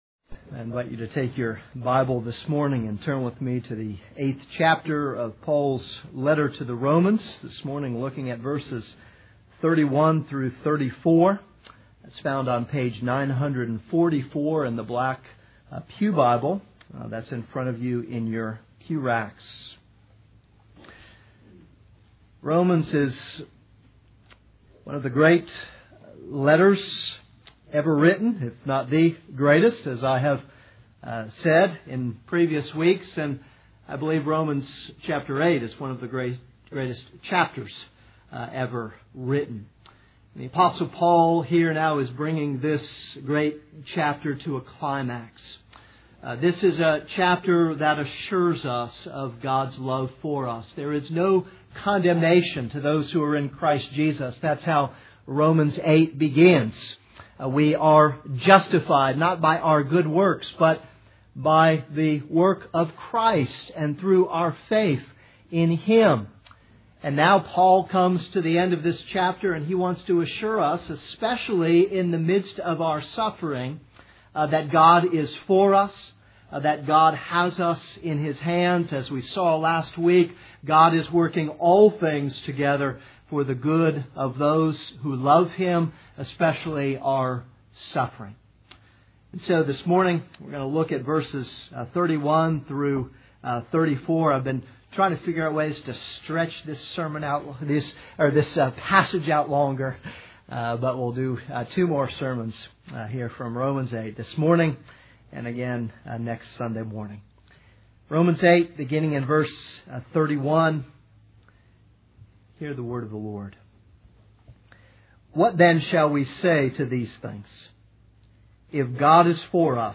This is a sermon on Romans 8:31-34.